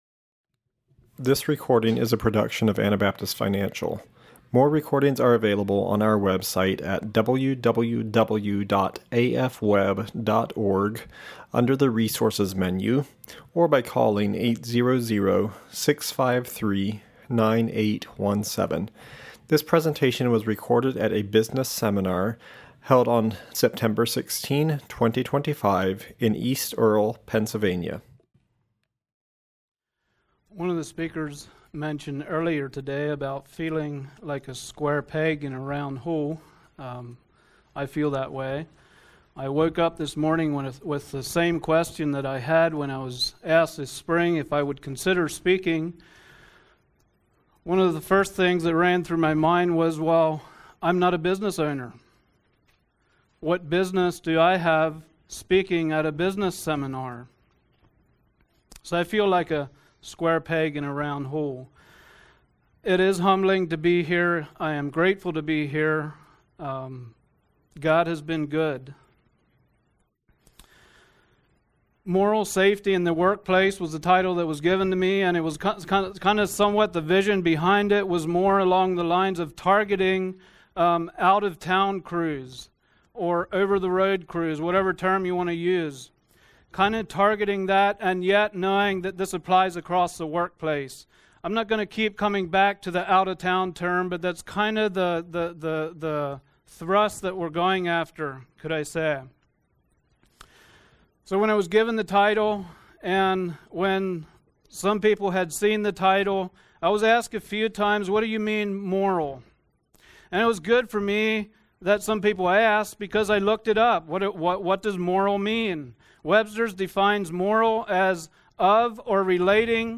Pennsylvania Business Seminar 2025 / Biblical Principles of Business and Work Proactive steps should be taken to protect moral safety.